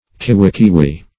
Search Result for " kiwikiwi" : The Collaborative International Dictionary of English v.0.48: Kivikivi \Ki`vi*ki"vi\, Kiwikiwi \Ki`wi*ki"wi\, n.; pl.